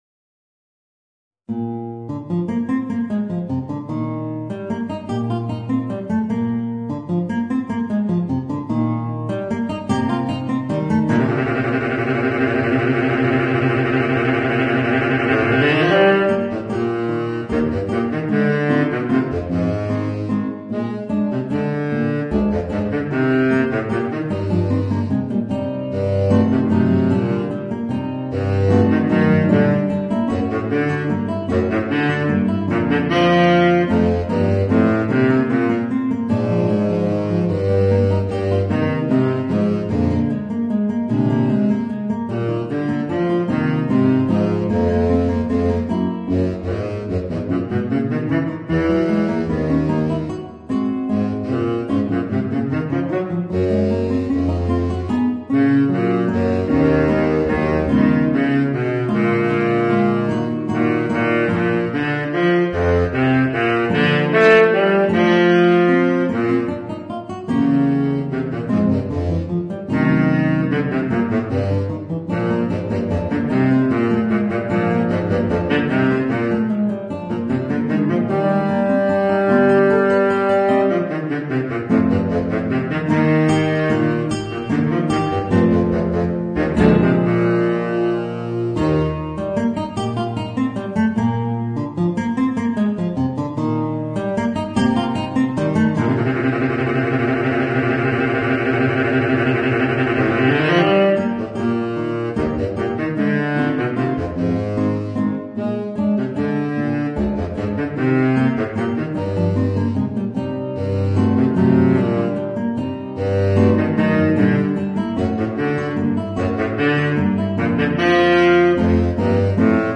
Voicing: Guitar and Baritone Saxophone